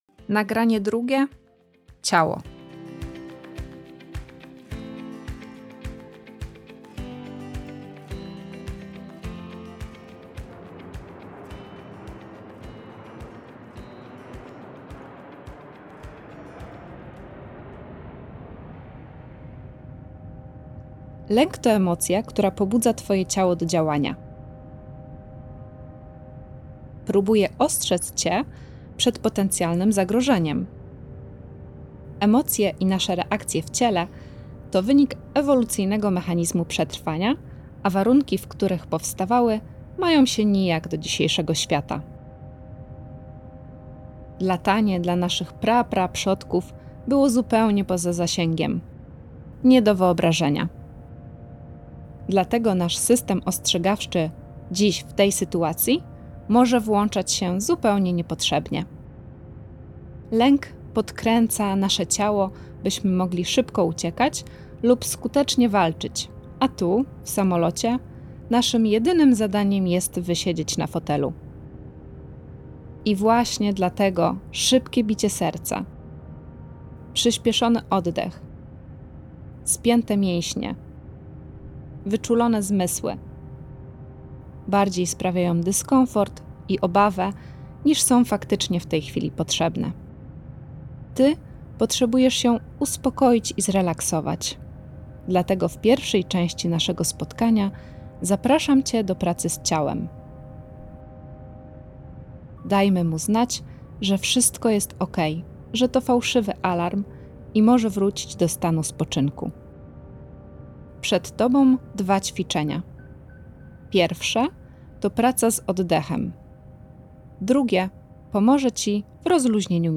Audiobook: WSPARCIE NA STARCIE
Praktyczne nagrania z instrukcjami głosowymi oraz ćwiczeniami, które pomogą zmniejszyć napięcie związane z podrożą samolotem.